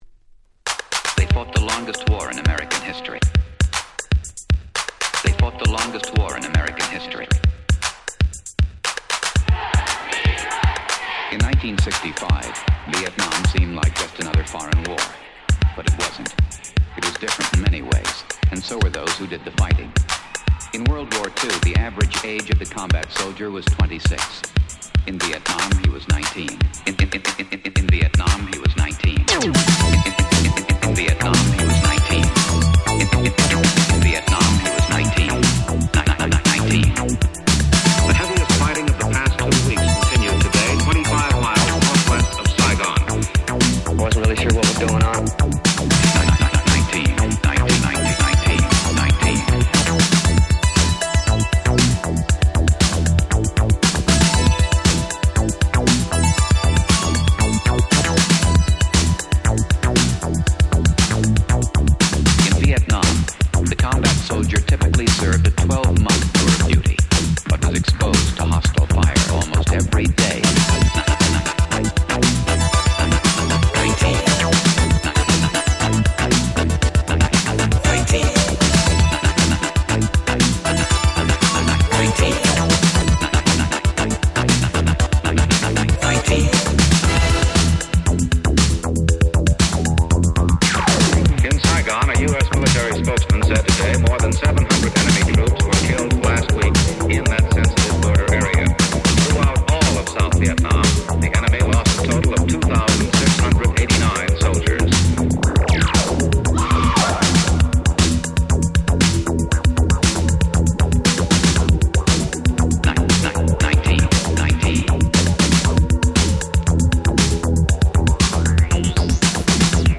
85' Electro Old School 大名曲。